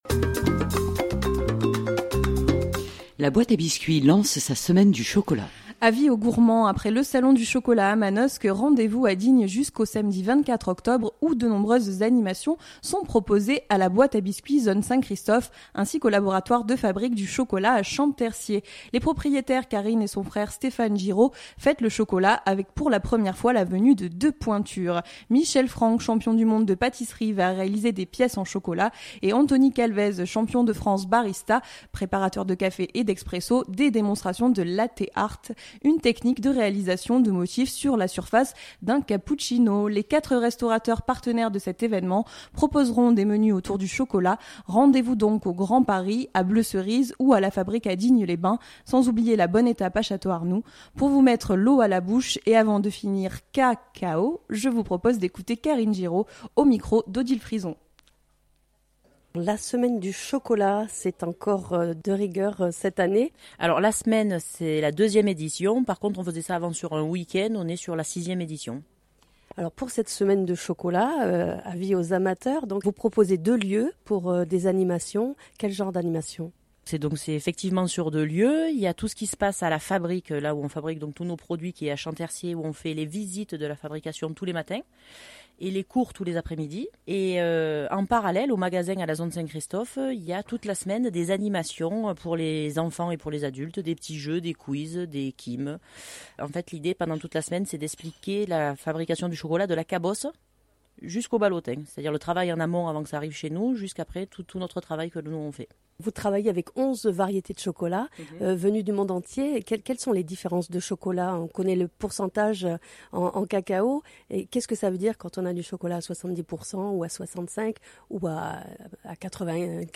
notre collègue a pu interviewer rapidement les restaurateurs de Digne-les-bains